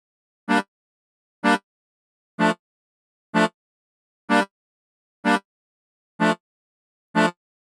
Libérez le potentiel sinistre et dubby du mélodica avec ce plugin léger et facile à utiliser.